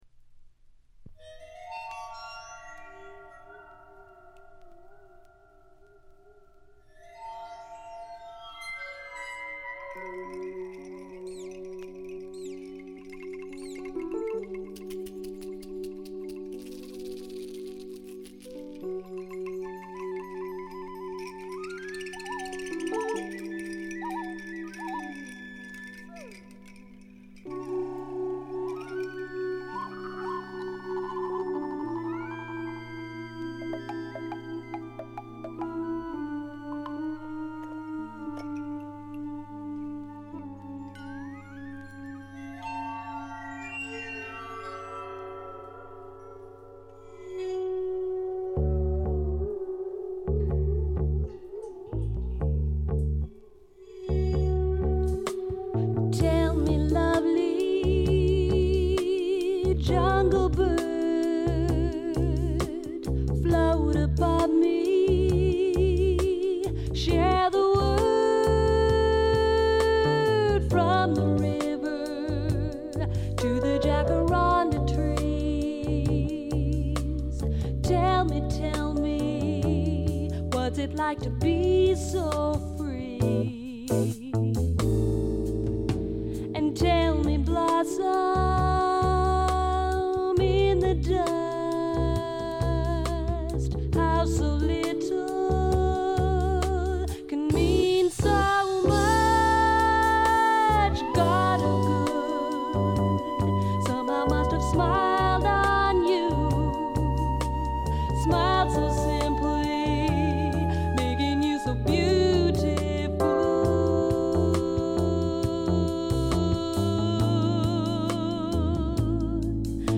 わずかなノイズ感のみ。
気を取り直して・・・これはシアトル産の自主制作盤で、知られざるAOR系女性シンガーソングライターの快作です。
試聴曲は現品からの取り込み音源です。
Recorded At - Sea-West Studios, Seattle